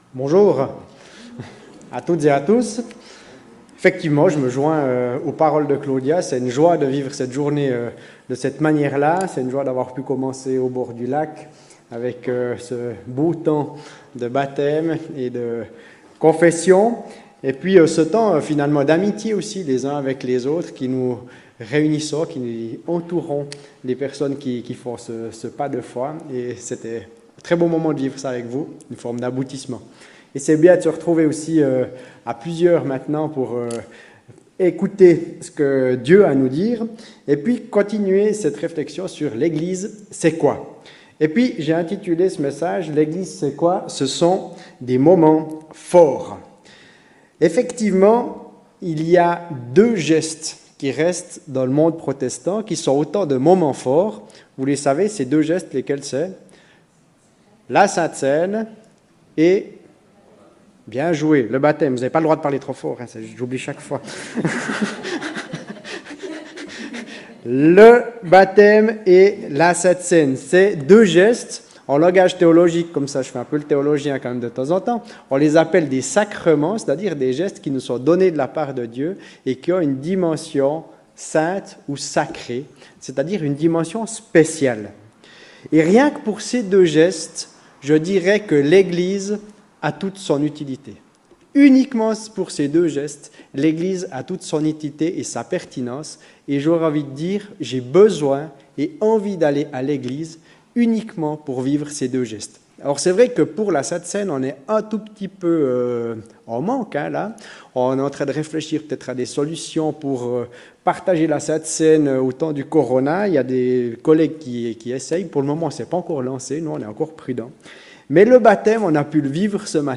Culte du 5 juillet 2020 L’Eglise c’est quoi 4/6